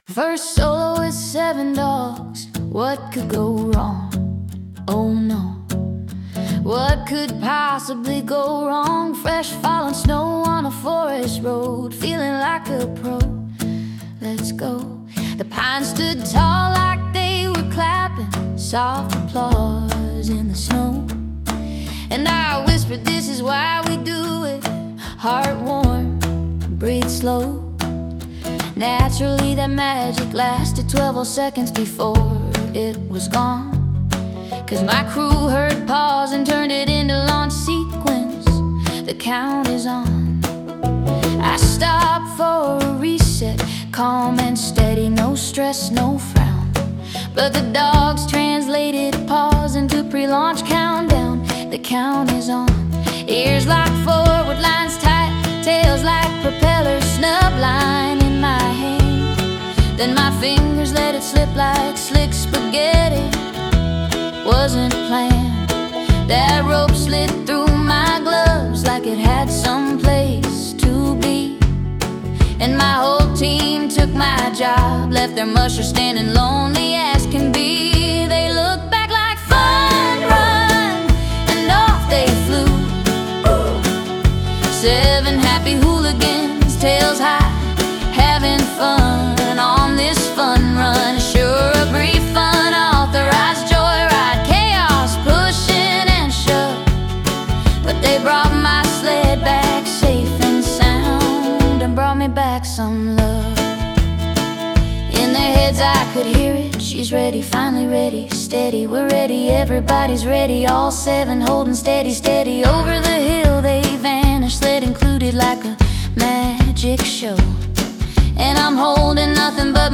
We’ve created this special collection of AI-generated songs to further enrich the stories shared here.